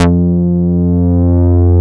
BAS_Prophet5 F3.wav